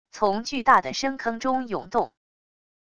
从巨大的深坑中涌动wav音频